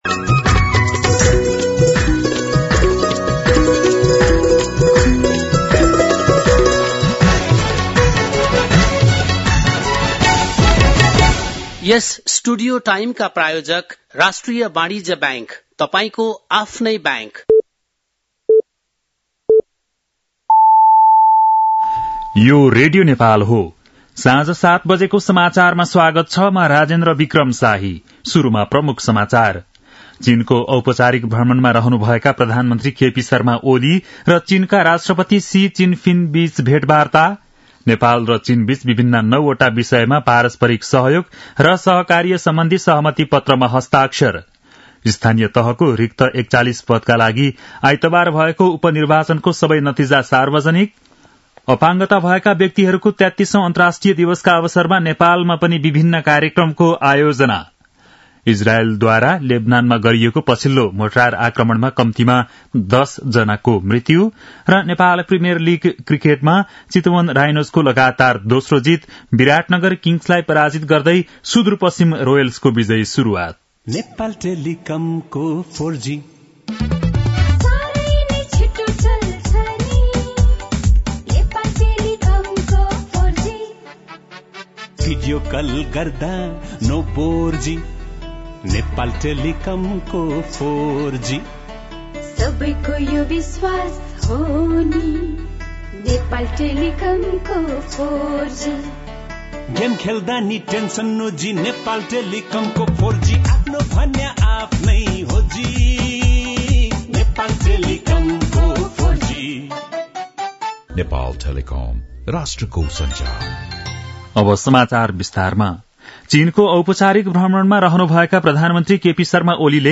बेलुकी ७ बजेको नेपाली समाचार : १९ मंसिर , २०८१
7-PM-Nepali-News-8-18.mp3